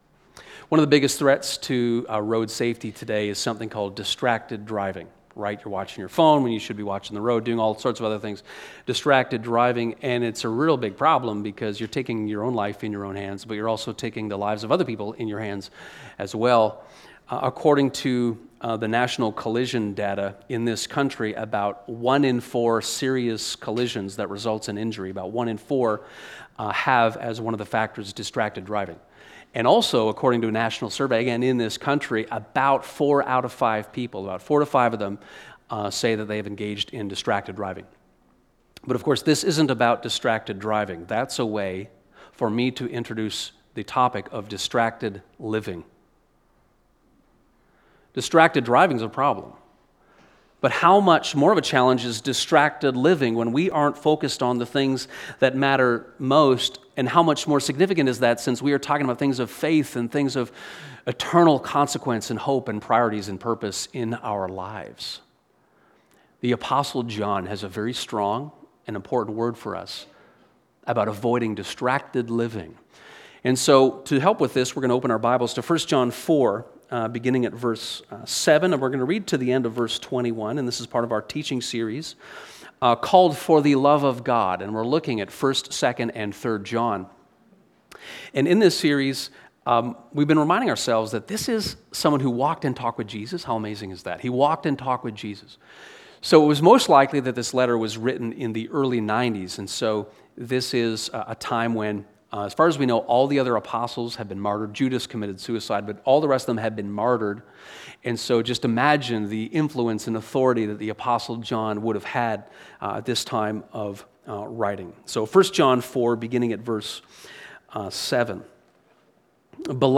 Forget about distracted driving. What about distracted living? (Sermon)